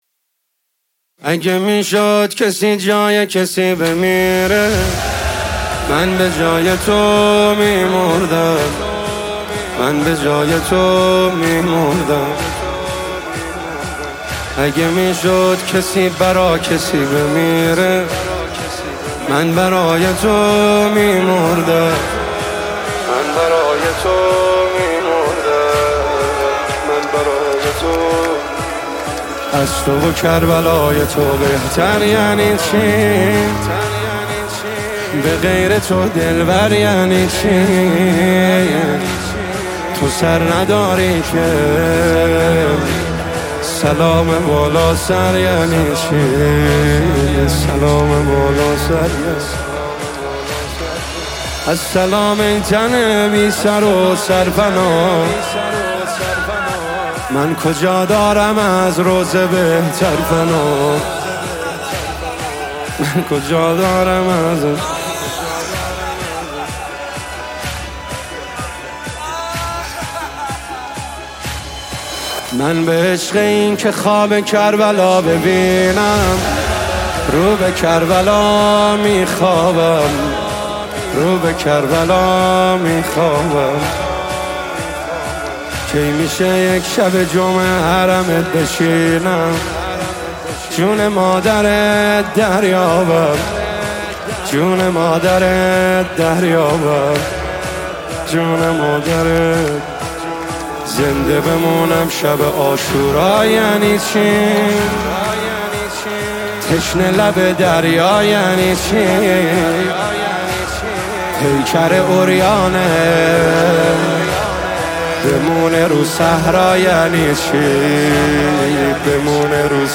نوای دلنشین